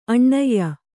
♪ aṇṇayya